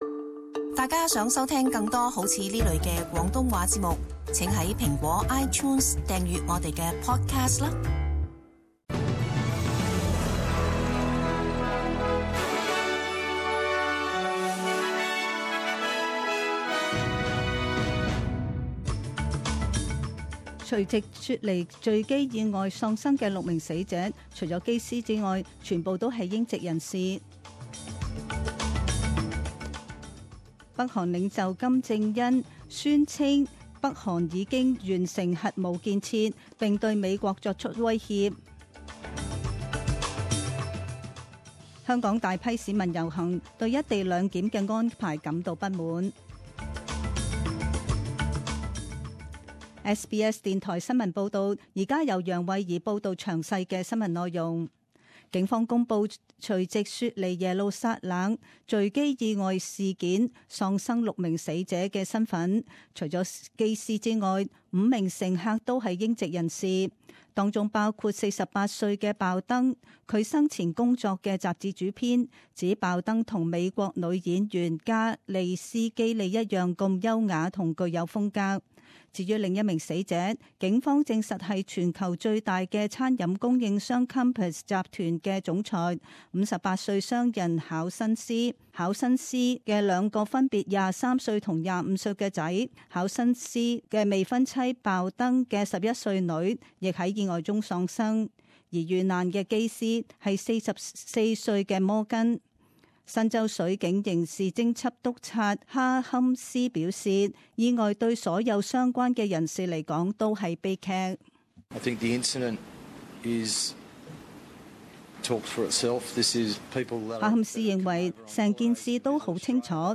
一月二日 [十點鐘新聞]
詳盡早晨新聞